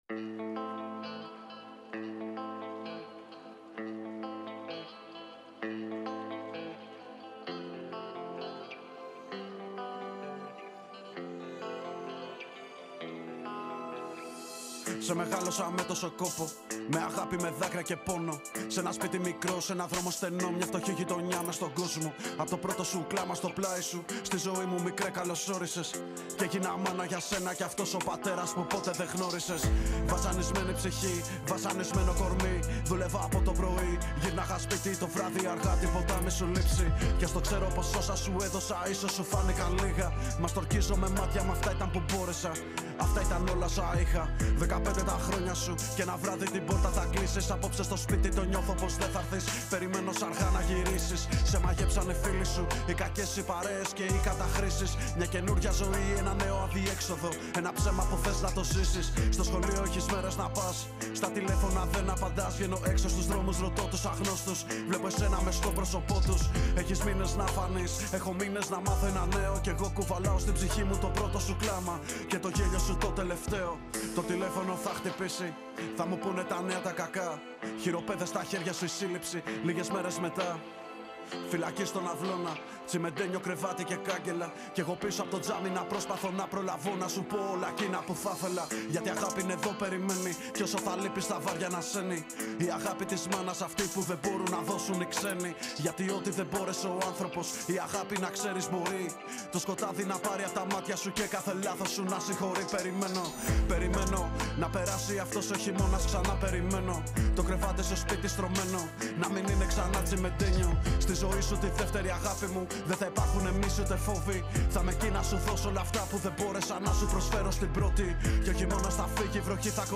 Η συνέντευξη πραγματοποιήθηκε την Τρίτη 3/12/2024Εκπομπή “καλημέρα” στον 9,58fm της ΕΡΤ3